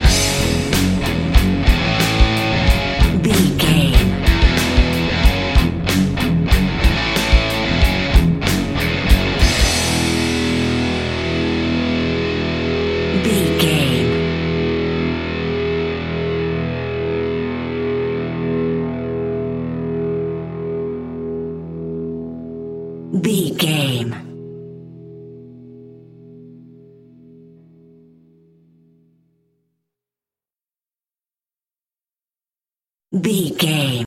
Ionian/Major
D
drums
electric guitar
bass guitar
Sports Rock
hard rock
lead guitar
aggressive
energetic
intense
nu metal
alternative metal